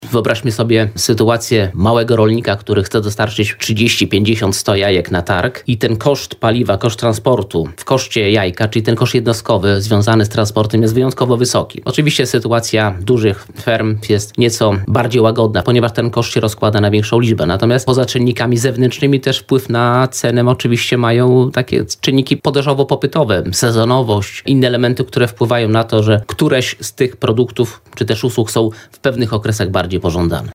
powiedział w porannej rozmowie Radia Lublin